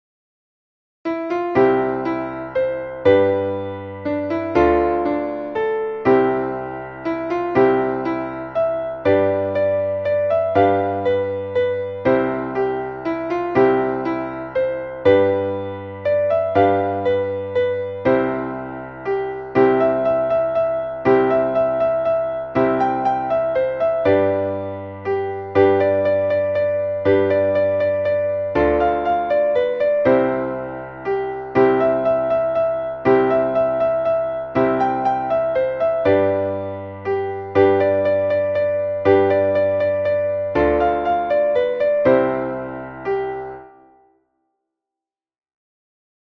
Traditionelles Frühlingslied